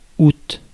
Ääntäminen
Vaihtoehtoiset kirjoitusmuodot (vanhahtava) aoust aout Ääntäminen France: IPA: /ut/ Tuntematon aksentti: IPA: /u/ IPA: /a.u/ IPA: /a.ut/ Haettu sana löytyi näillä lähdekielillä: ranska Käännös Ääninäyte Erisnimet 1.